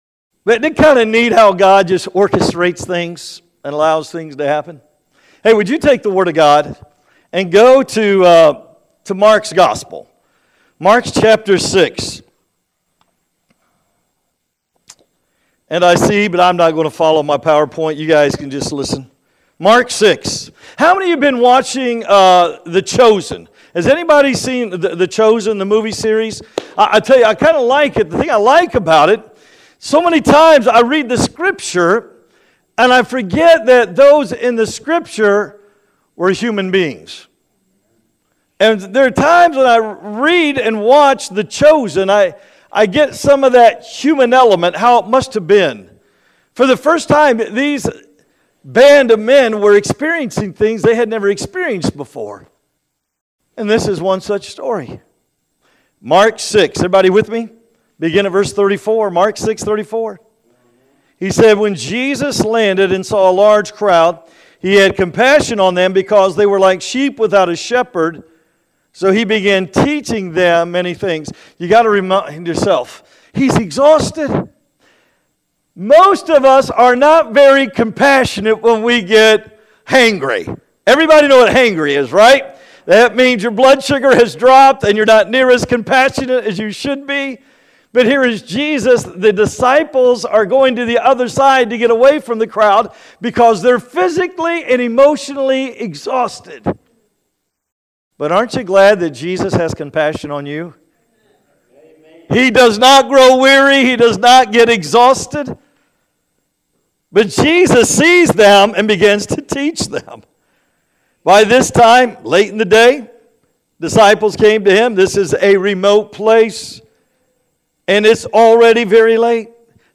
God Will Provide-A.M. Service